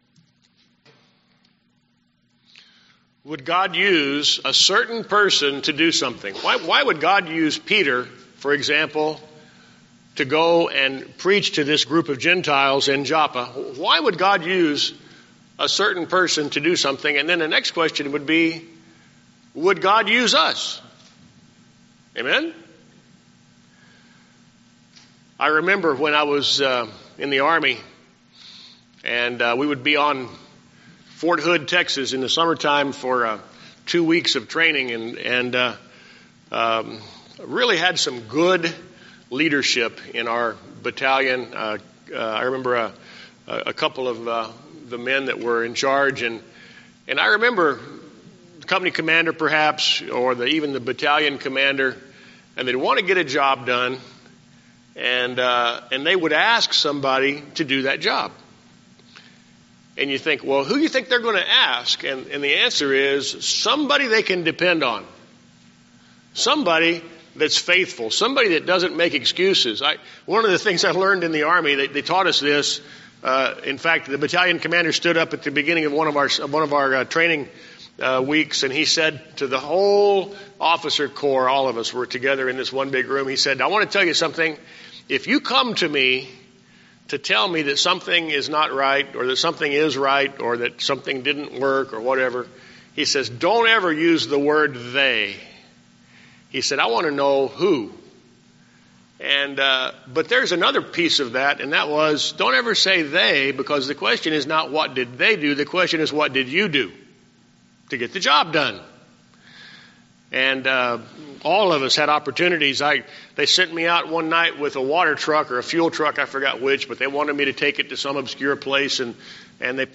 Email Details Series: Sermons Date